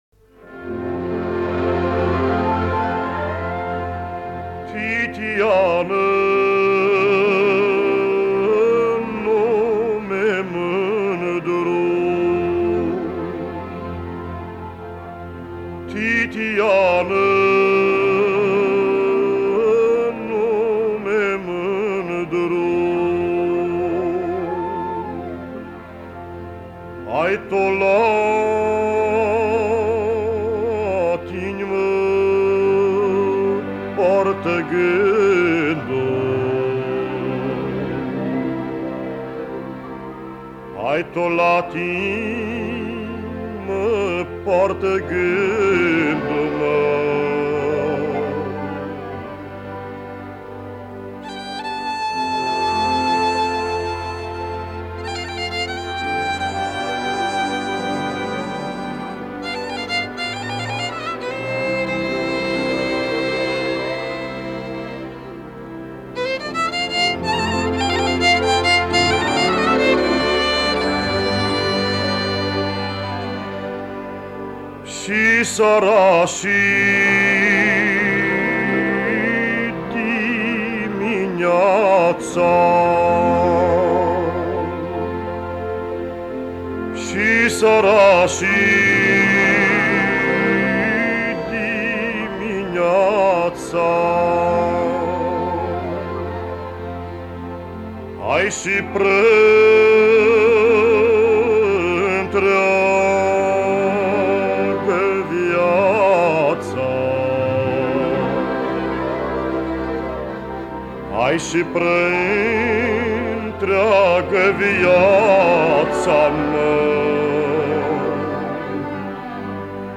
Înregistrări de arhivă